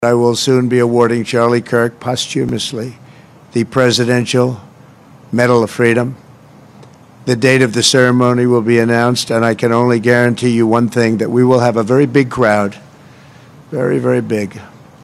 În cadrul discursului, președintele Donald Trump a anunțat că îl va decora post-mortem pe Charlie Kirk, asasinat aseară, cu Medalia Prezidențială a Libertății, una dintre cele mai înalte distincții civile din Statele Unite ale Americii.
11sept-20-Trump-decorare-Kirk-NETRADUS-.mp3